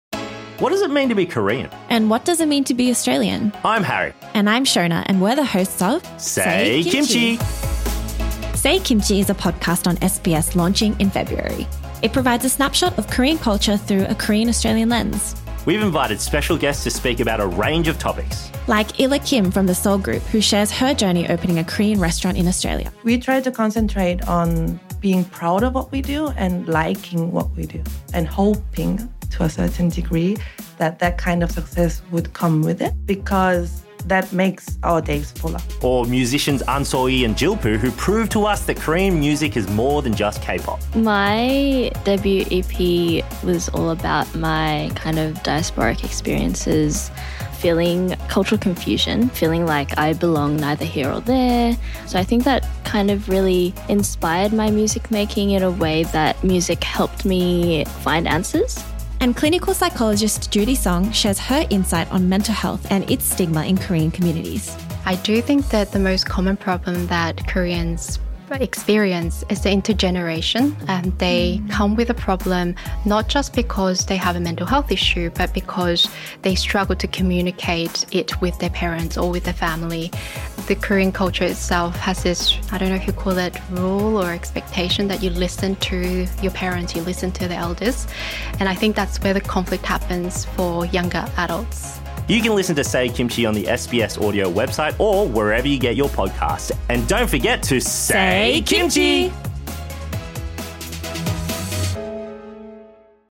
Listen for candid chats about growing up Korean Australian, Korean beauty standards, K-pop, Korean food, battling stereotypes and more.